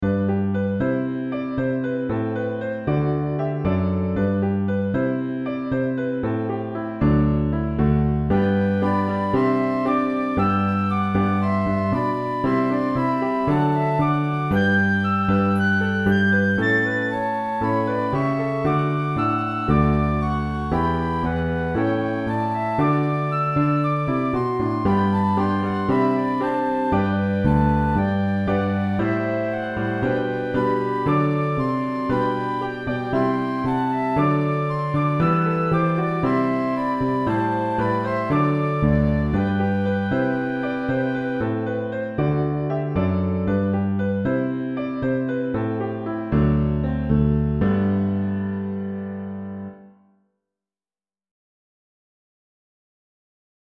third pair) all feature a flute sound, they can be played by any instrument fitting for worship.
high instrumental descant